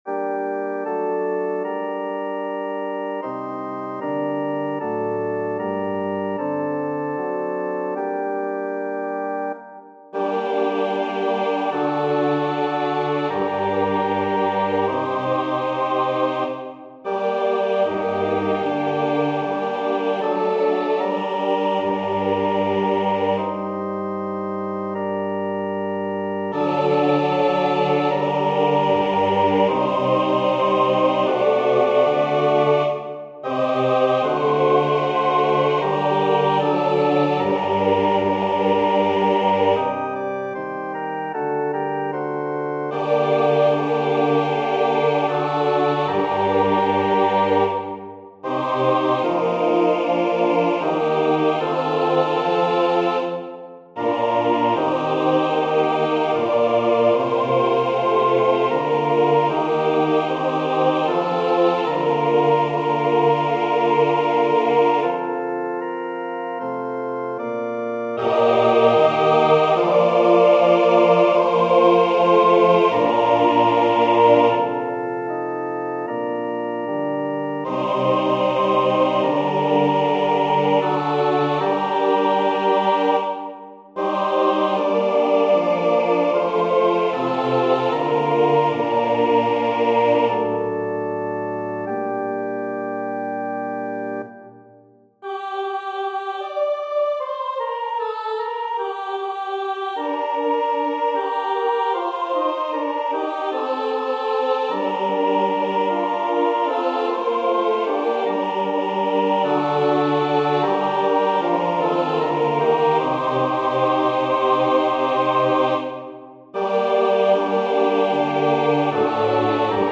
Number of voices: 4vv Voicing: SATB Genre: Sacred, Motet
Language: Czech Instruments: Organ